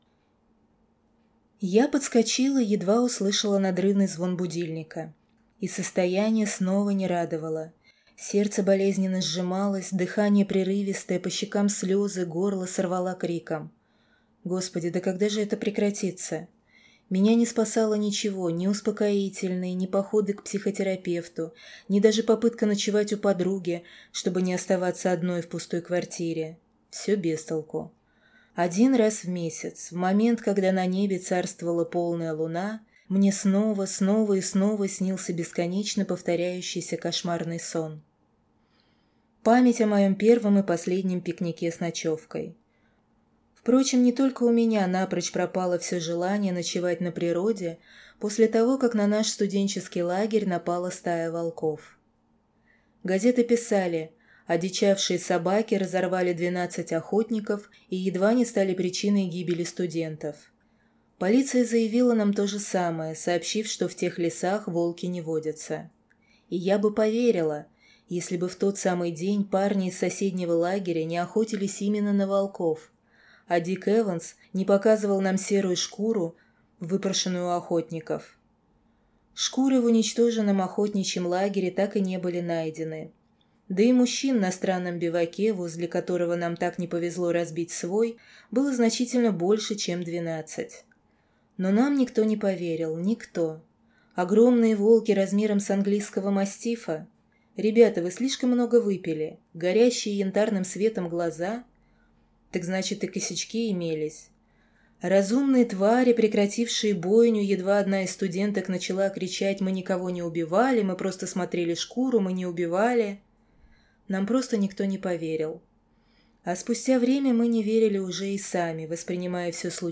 Аудиокнига Замок Оборотня - купить, скачать и слушать онлайн | КнигоПоиск